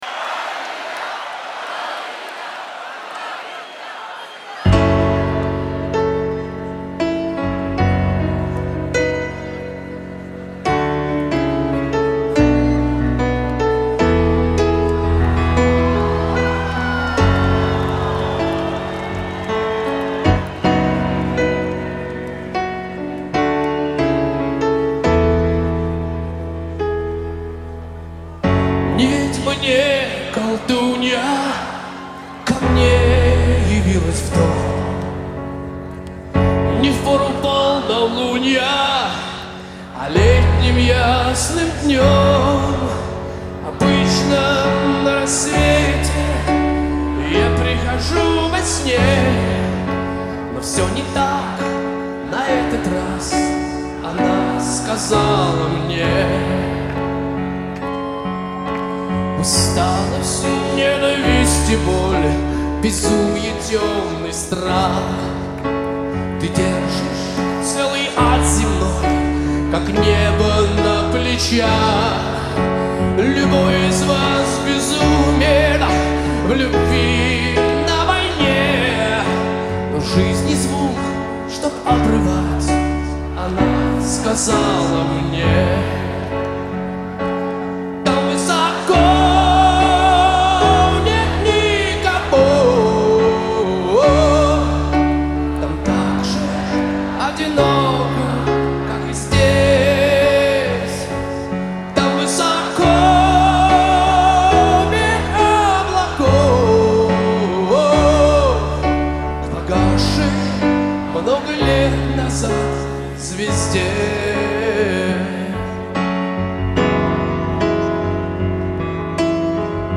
Жанр: Heavy Metal